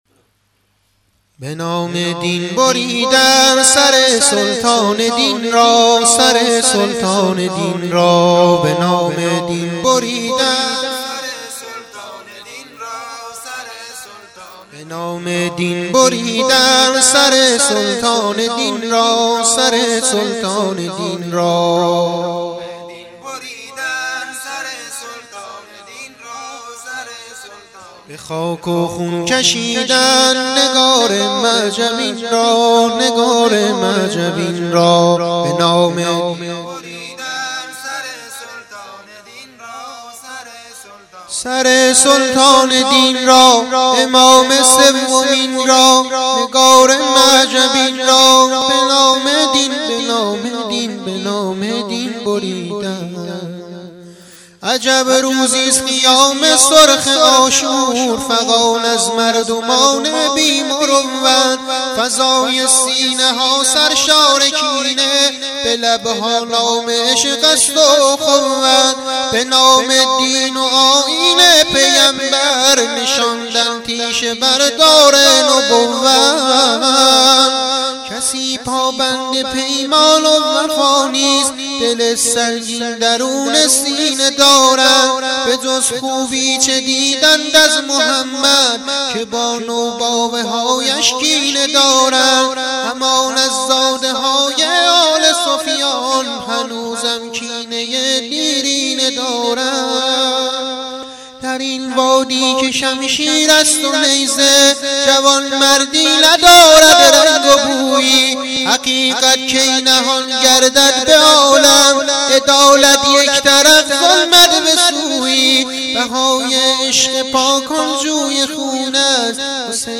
آهنگ سبک نوحه